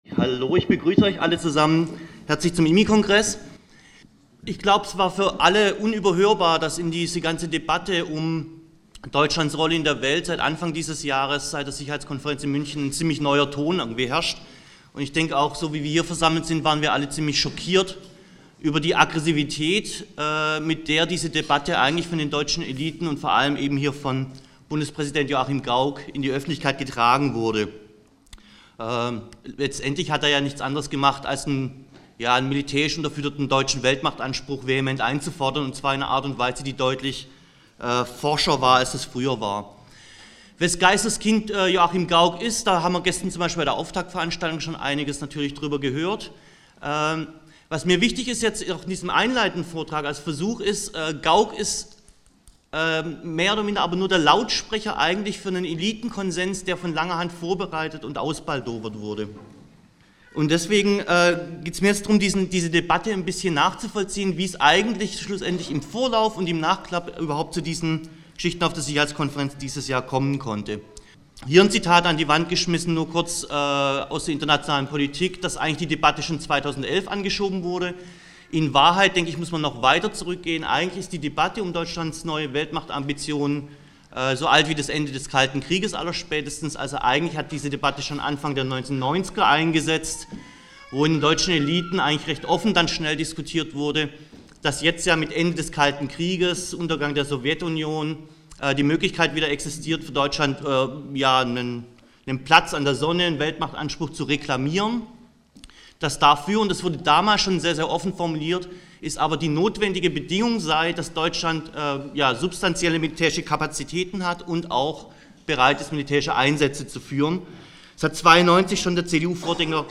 Der Vortrag lief im Rahmen einer Liveübertragung vom IMI-Kongress 2014.